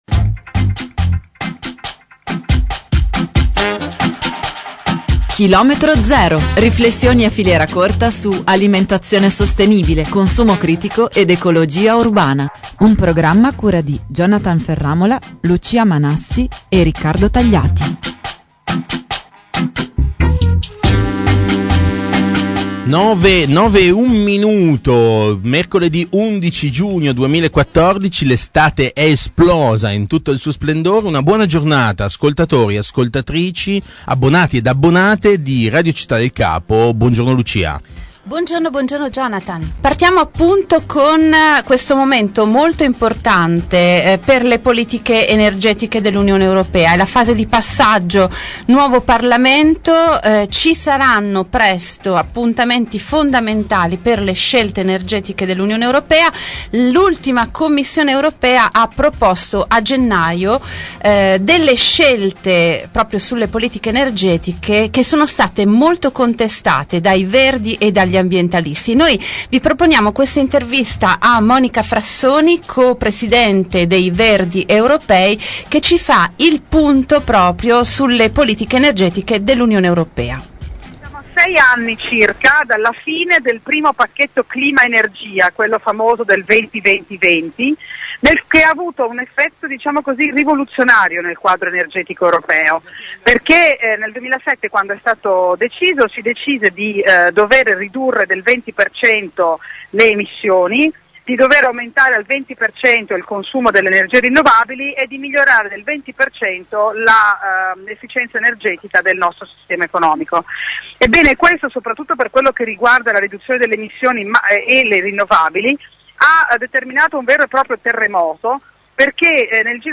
E per di più l’Italia è passata dalle posizioni avanzate espresse dal governo Letta a quelle più filo fossili del neoministro per l’ambiente Galletti L’intervista a Monica Frassoni è all’inizio della puntata di km0 di questa settimana. km11_6 Download